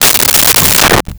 Dresser Drawer Close 03
Dresser Drawer Close 03.wav